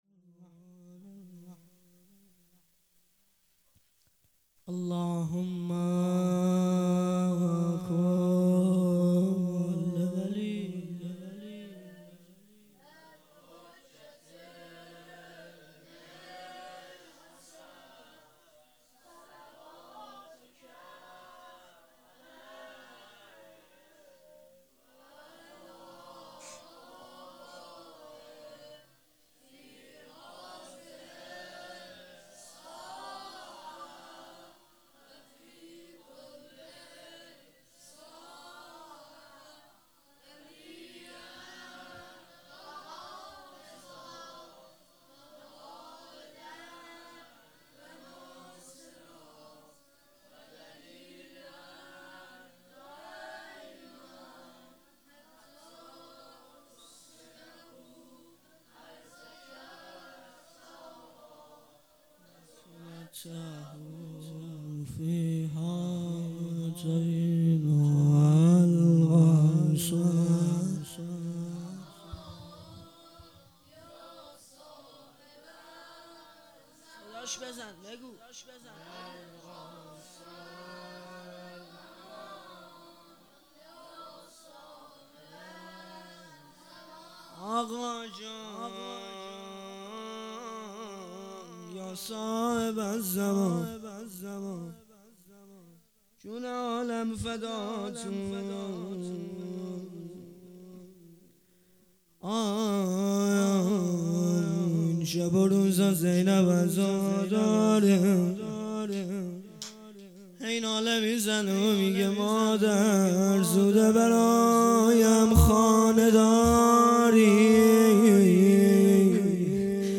هیئت جنت الرقیه(س) خمینی شهر